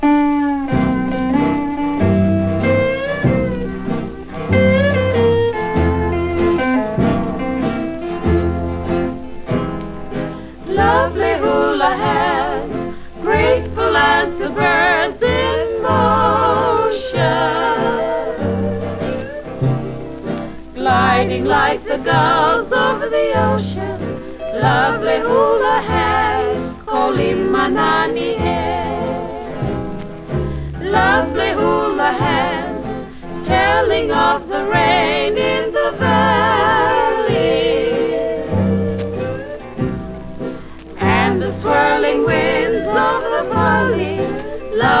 歌唱、楽曲、楽器とすべてがハワイ満点、魅惑的な良盤です。
World, Hawaii　USA　12inchレコード　33rpm　Mono